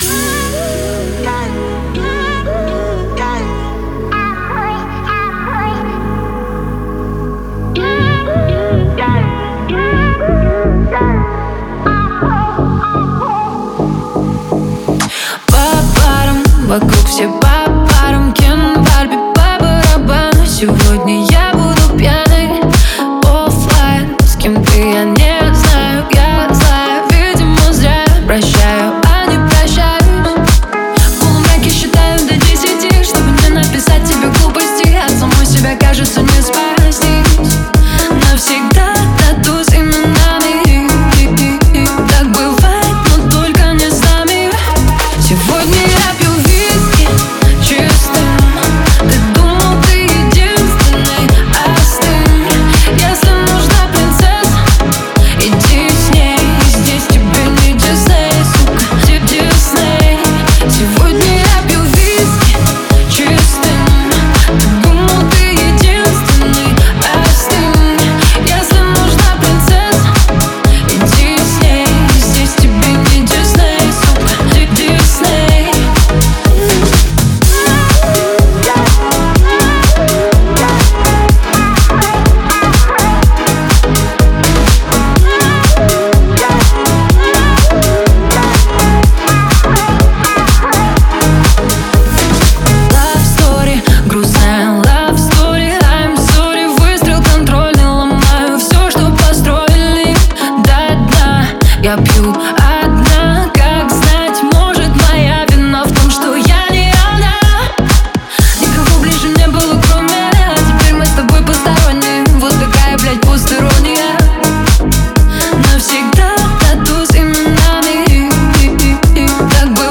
• Ремикс